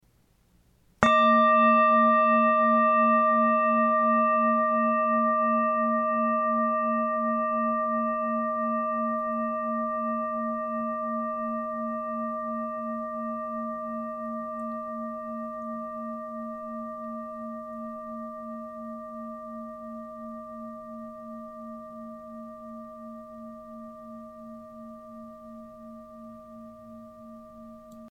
KLANGPROBEN
4 Klangschalen für die Klangmassage
Sehr gute Klangqualität - sehr lange anhaltender Klang
Grundton 231,50 Hz
1. Oberton 624,52 Hz